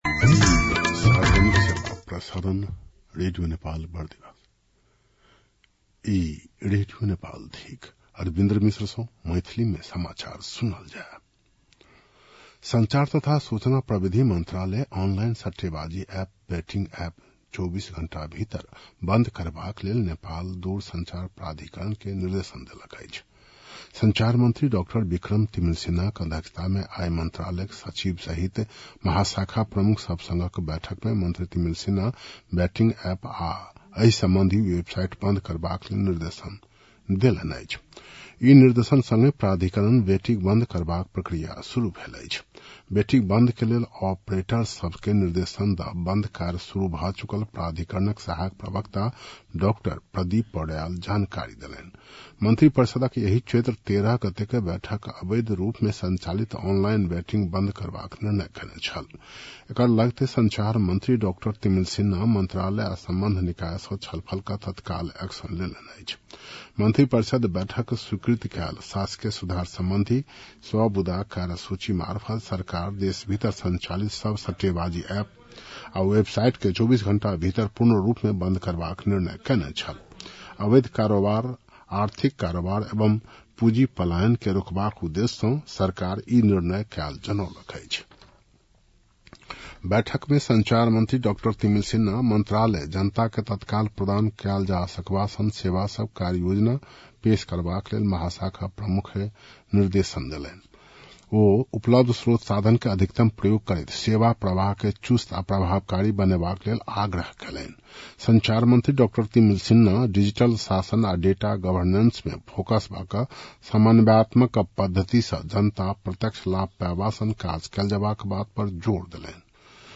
मैथिली भाषामा समाचार : १५ चैत , २०८२
6.-pm-maithali-news-1-6.mp3